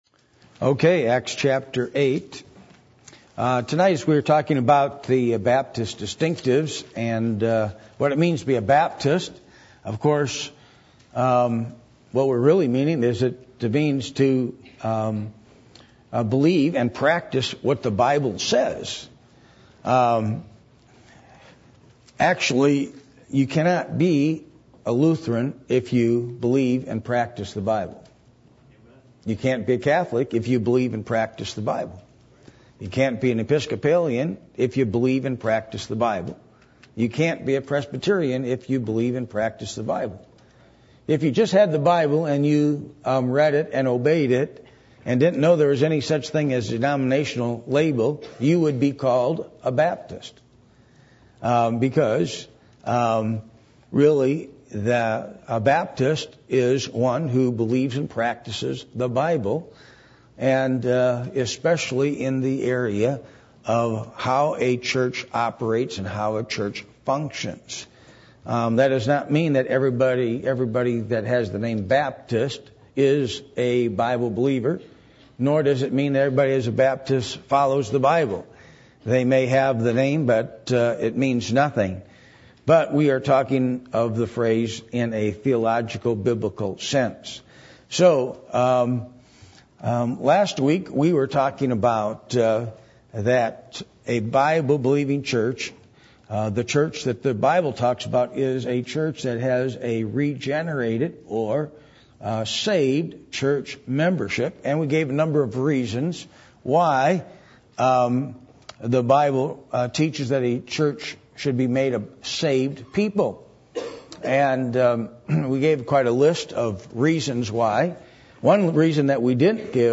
Acts 8:35-39 Service Type: Midweek Meeting %todo_render% « What Will You Attempt For The Lord?